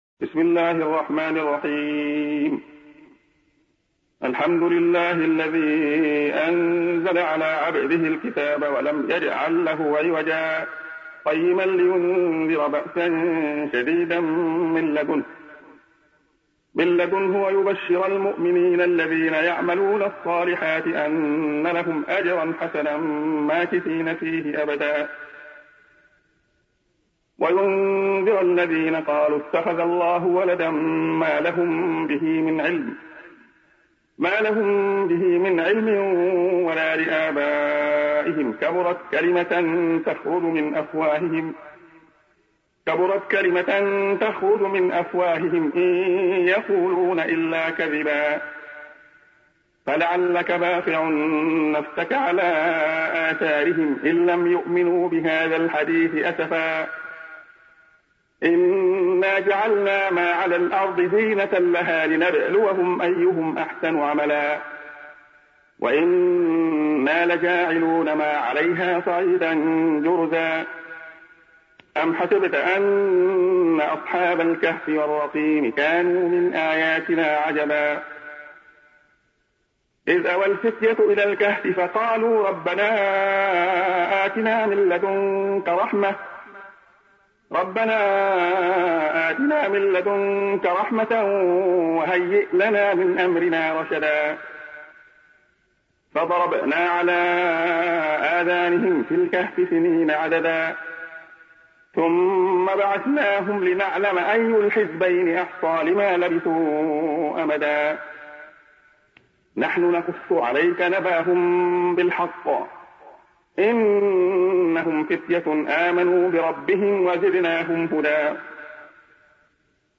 سُورَةُ الكَهۡفِ بصوت الشيخ عبدالله الخياط